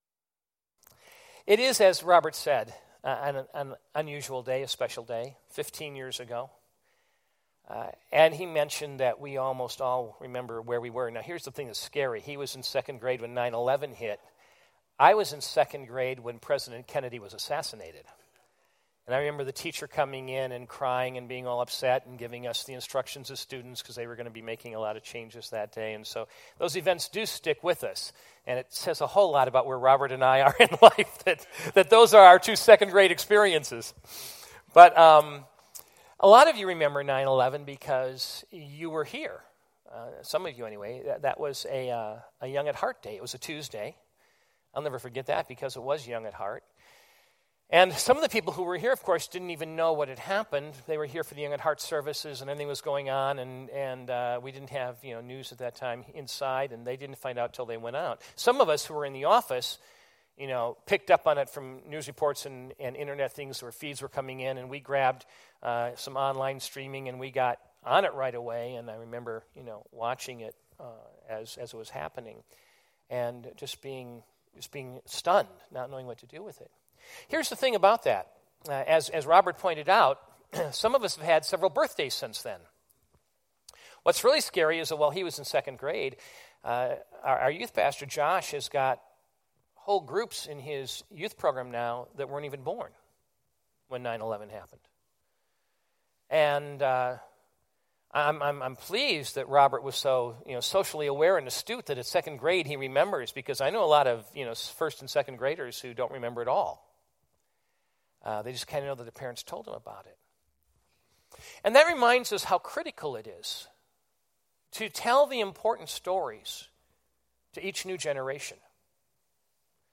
2016 Categories Sunday Morning Message Download Audio Joshua 27